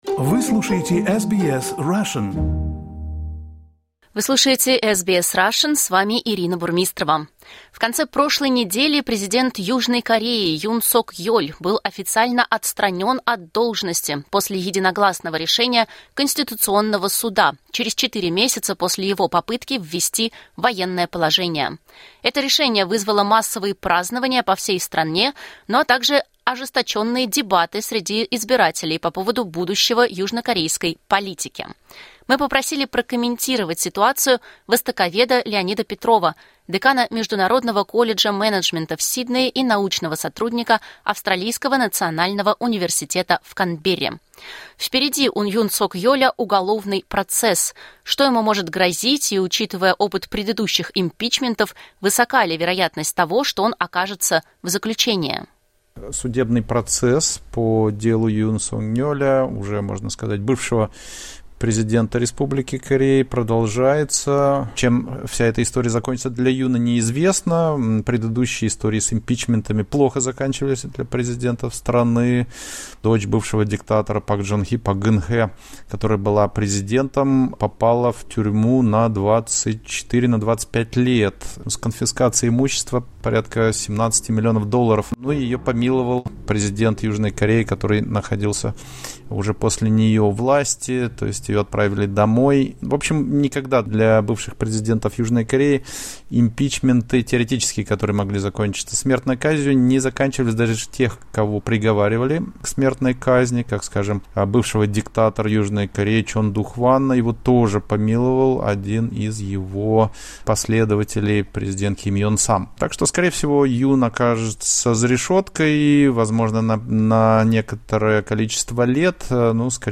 Комментирует востоковед